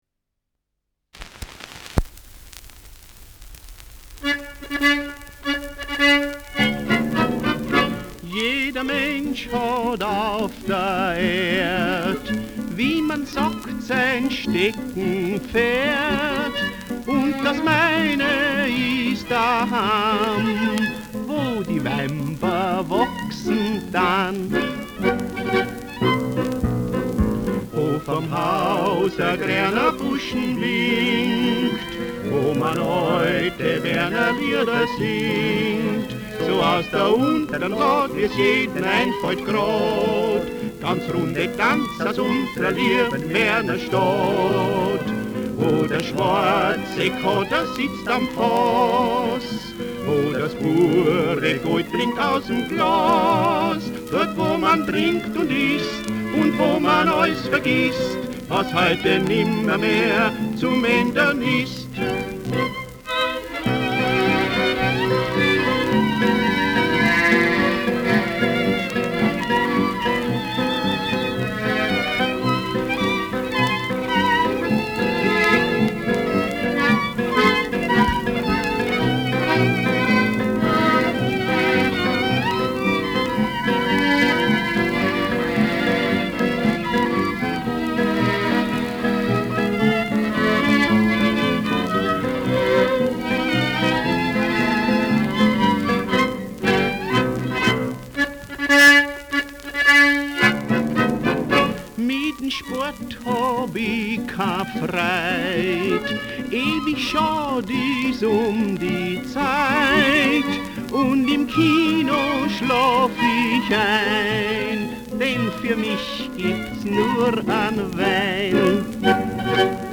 Schellackplatte
Stärkeres Grundrauschen : Durchgehend leichtes Knacken